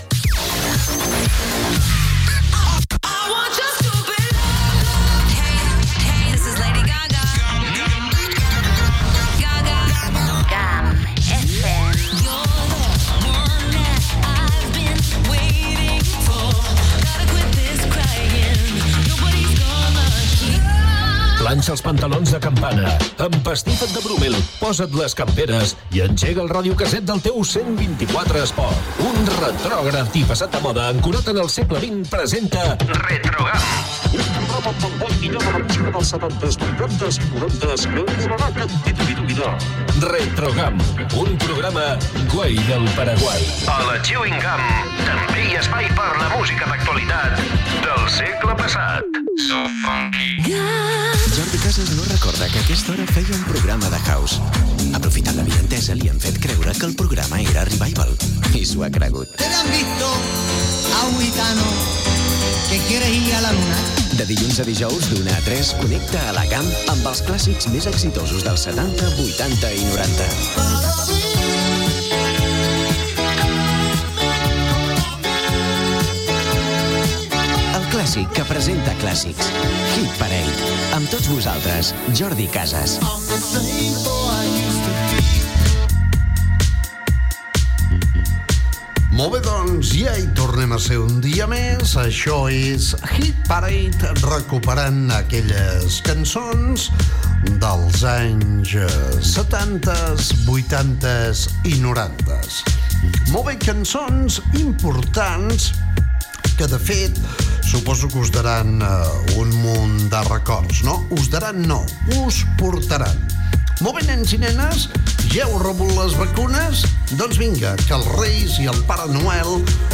Indicatiu de la ràdio, promoció de "Retrogram", careta del programa, presentació, els regals de Reis, salutació a les comarques on s'escolta l'emissora i tema musical
Musical